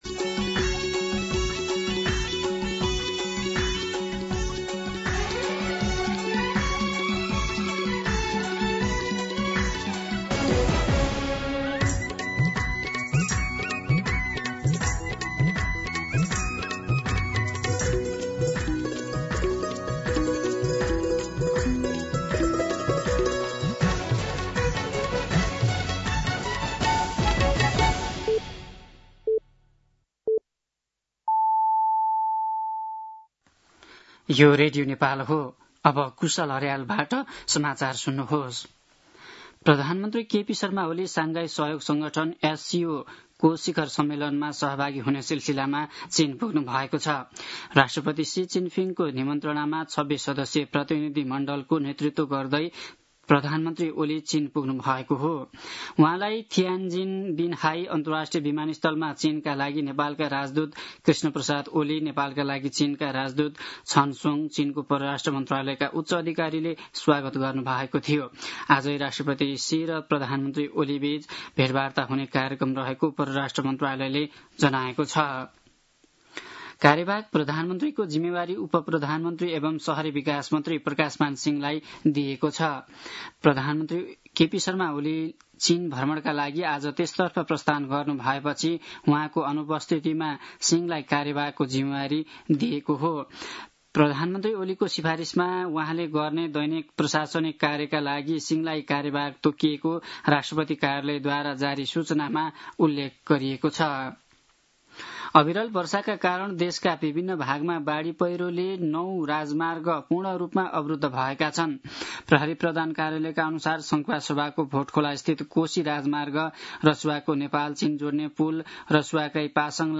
दिउँसो ४ बजेको नेपाली समाचार : १४ भदौ , २०८२
4pm-News-05-14.mp3